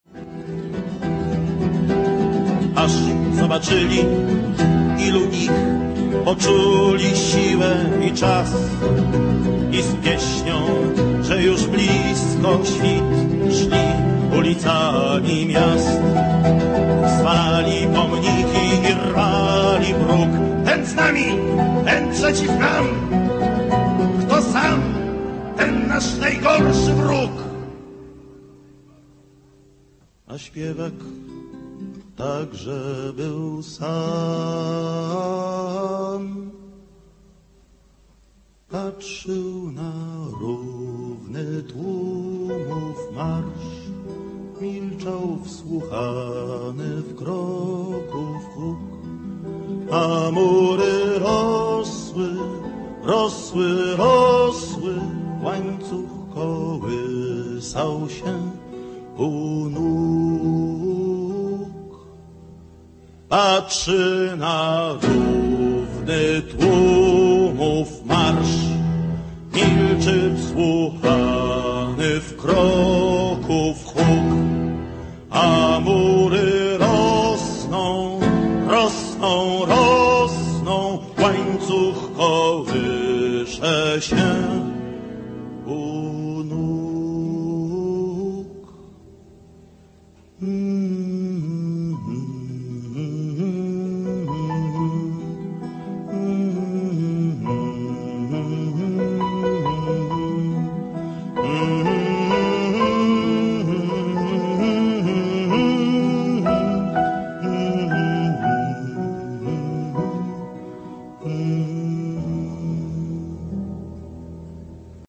Очень сильная песня и блестящее исполнение.